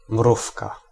Ääntäminen
Ääntäminen CA : IPA: [fuʁ.mi] Canada (Montréal): IPA: /fuʁ.mi/ Haettu sana löytyi näillä lähdekielillä: ranska Käännös Konteksti Ääninäyte Substantiivit 1. mrówka {f} eläintiede Suku: f .